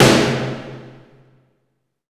Index of /90_sSampleCDs/Roland L-CD701/SNR_Snares 2/SNR_Sn Modules 2
SNR HUGE 0QL.wav